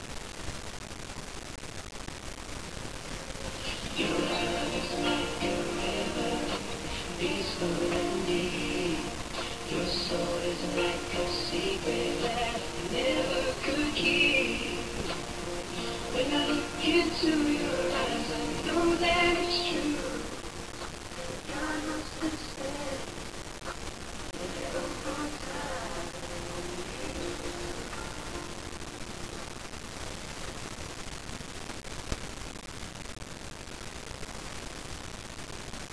The medleys are distorted to make it more challenging.